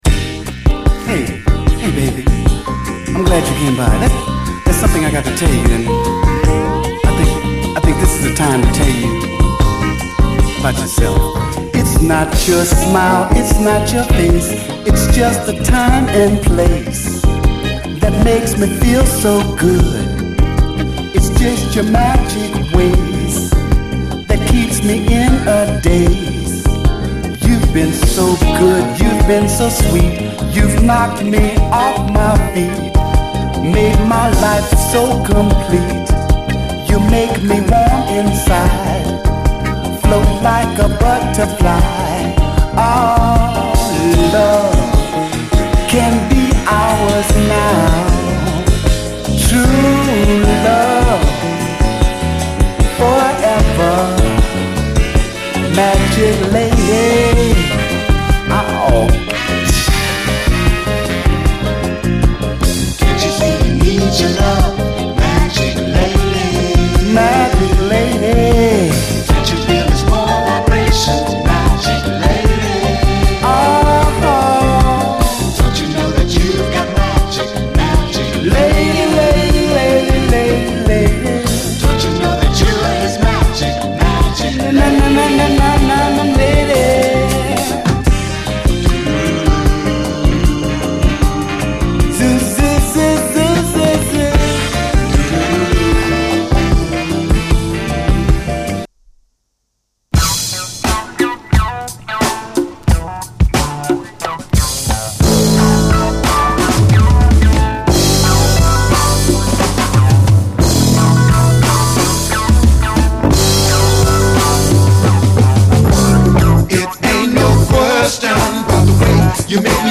SOUL, 70's～ SOUL, 7INCH